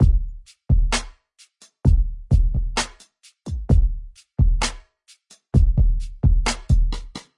Drum Loops " Rn B130bpm
描述：这是用Fruity loops制作的RnB(ish)鼓循环。几乎没有后期过滤或处理。在程序中进行了压缩，并添加了一些微妙的混响。
Tag: 130bpm 节拍 循环 节奏蓝调